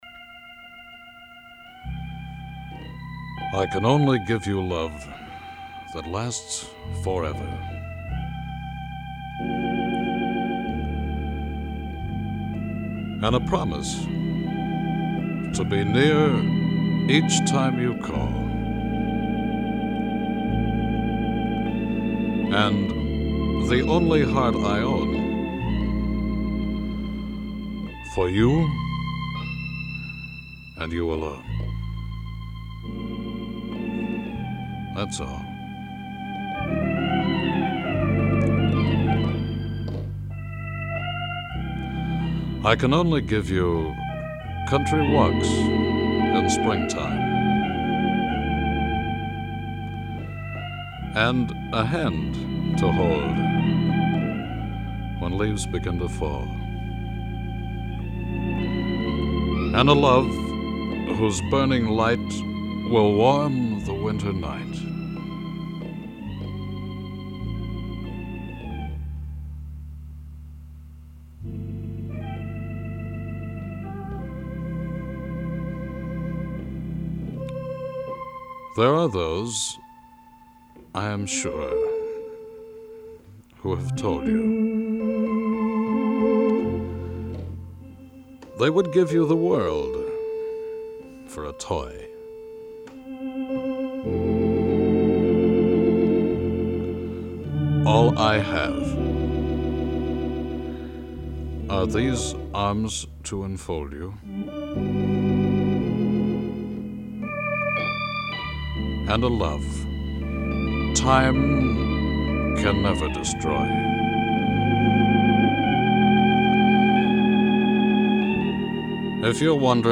evocative writing style and commanding vocal gift